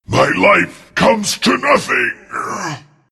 Play, download and share Huskar death original sound button!!!!
husk_death_08.mp3